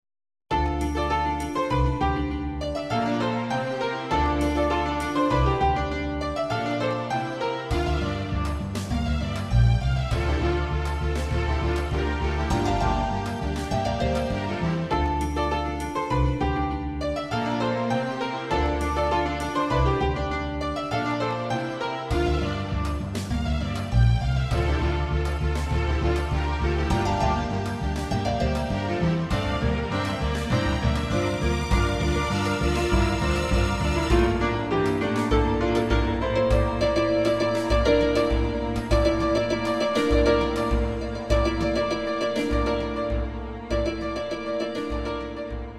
Sintonía de cabecera para un programa de Noticias